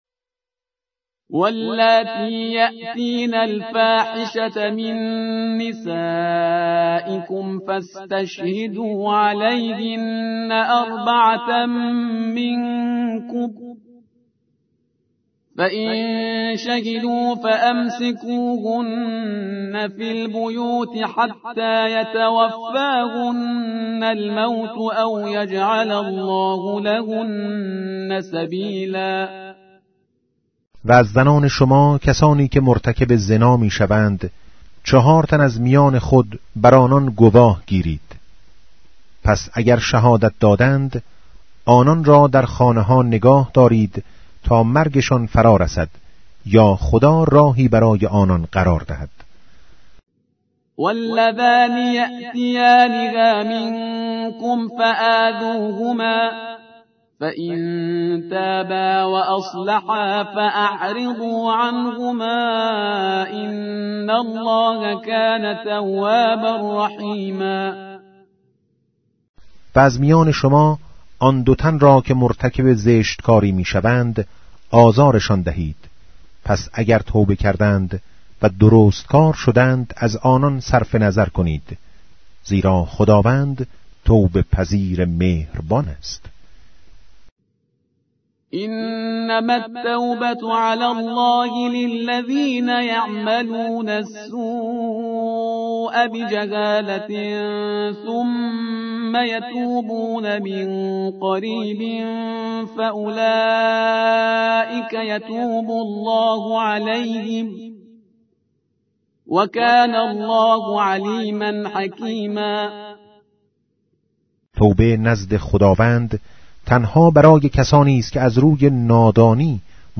به گزارش آوای سیدجمال،  از آنجا که پیامبر اکرم(ص)، در آخرین وصیت خود، از قرآن به‌عنوان ثقل اکبر یاد کرده و تأکید بر توجه به این سعادت بشری داشت،  بر آن شدیم در بخشی با عنوان «کلام نور» تلاوتی از چراغ پرفروغ قرآن كه تلألو آن دل‌های زنگار گرفته و غفلت زده را طراوتی دوباره می‌بخشد به صورت روزانه تقدیم مخاطبان خوب و همیشه همراه آوای سیدجمال کنیم.